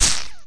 q3rally/baseq3r/sound/weapons/lightning/lg_hit2.ogg at ec9fa188f79640f154e20e6ed697094dc8cc9c3a